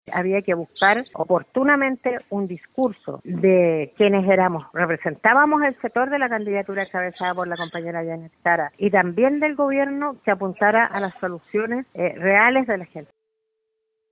La diputada Lorena Pizarro (PC) sostuvo que solo a partir de una evaluación honesta, crítica y autocrítica es posible sacar lecciones políticas de la derrota. Además, apuntó a cómo no se encontró un relato que respondiera a las necesidades de la gente.